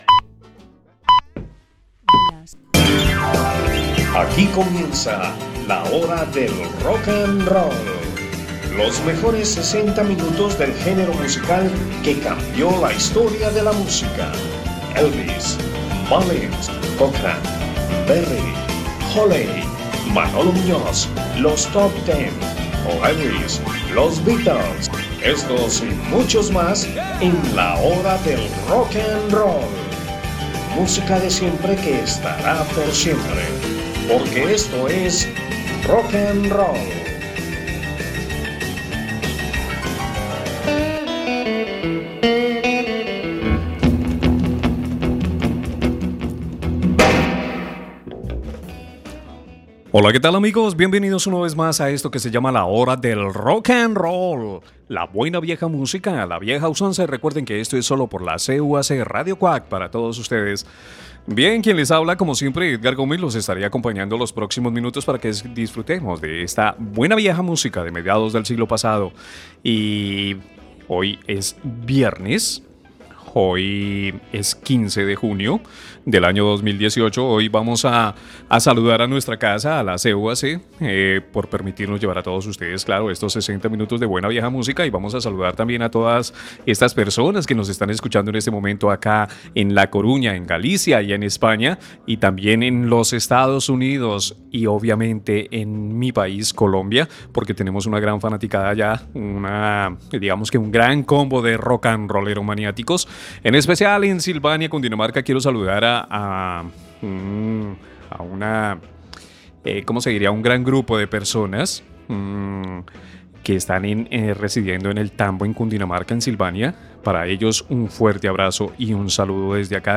...música de los años 50, 60 y 70...